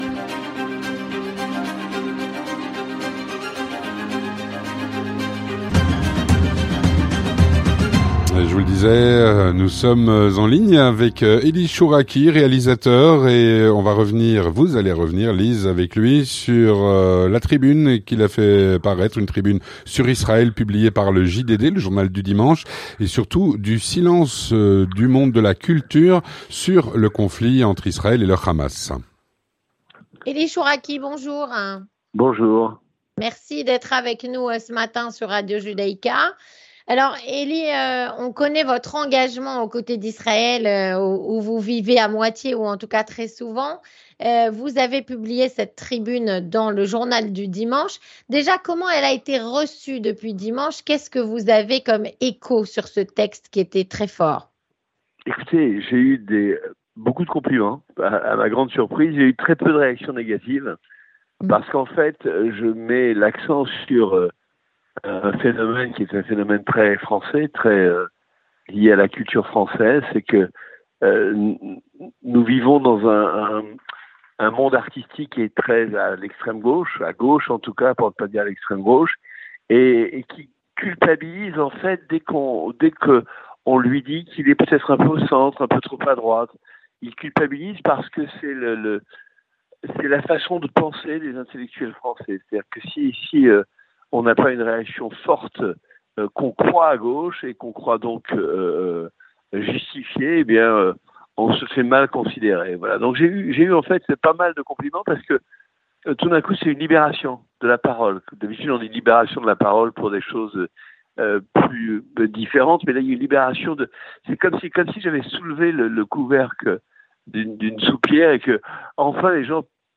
Avec Elie Chouraqui, réalisateur.